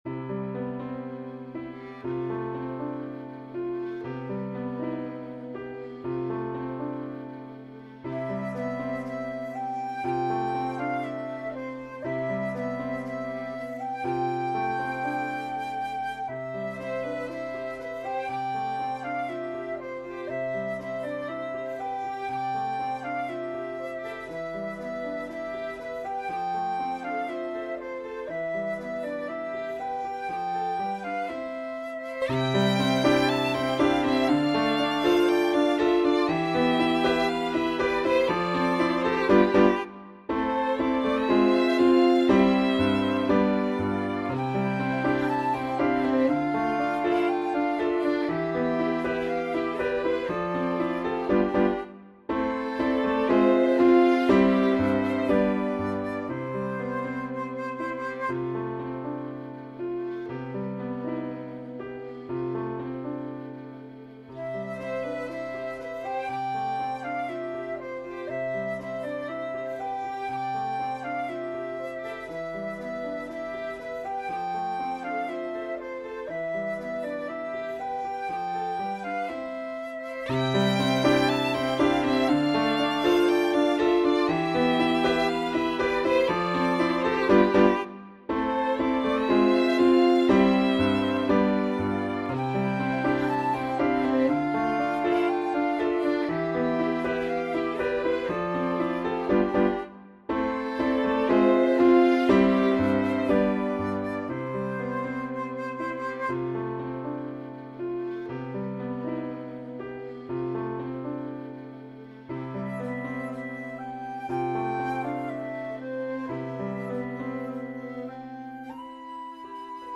Inhalt: Drei Trios für Flöte, Violine und Klavier.